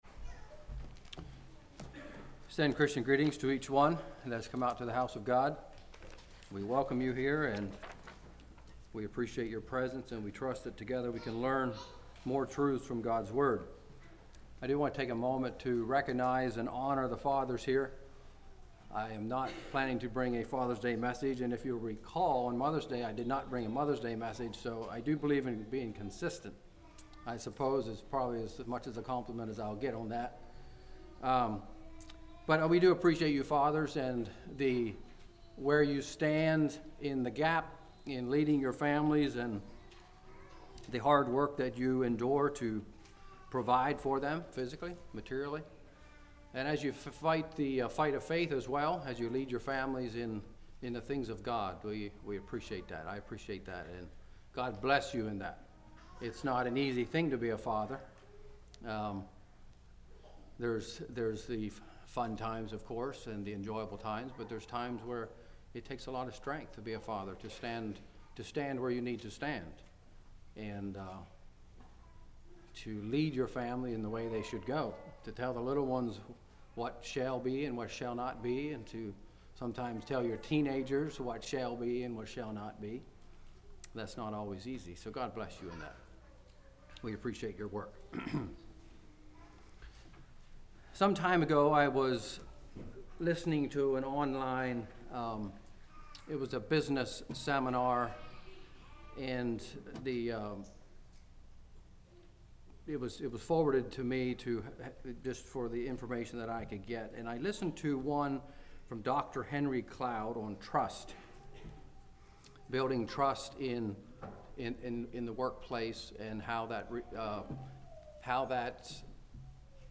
Play Now Download to Device Building Trust In The Brotherhood Congregation: Blue Ridge Speaker